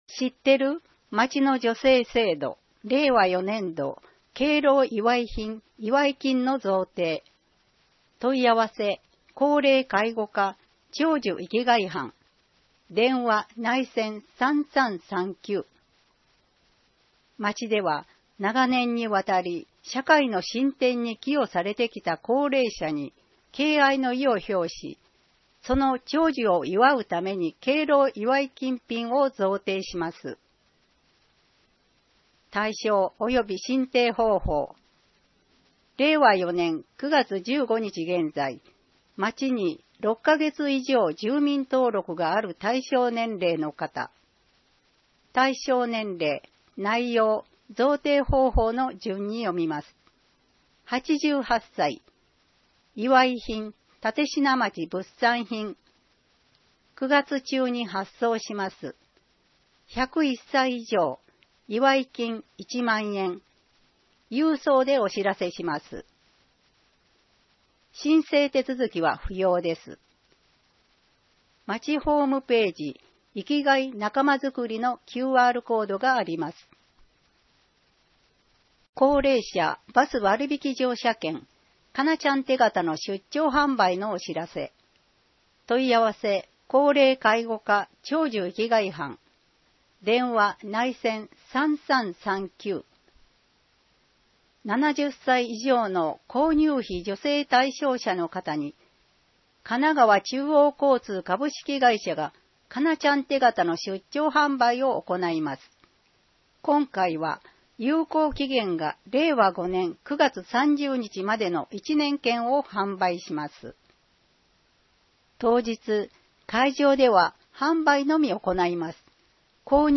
新規バス路線（桜台小沢線経由） (PDFファイル: 840.1KB) アルバイトを始める前に知っておきたい「6つのポイント」 (PDFファイル: 646.9KB) 保健ガイド (PDFファイル: 1.2MB) お茶の間通信 (PDFファイル: 2.0MB) あいかわカレンダー (PDFファイル: 1.2MB) 音声版「広報あいかわ」 音声版「広報あいかわ」は、「愛川町録音ボランティアグループ かえでの会」の皆さんが、視覚障がい者の方々のために「広報あいかわ」を録音したものです。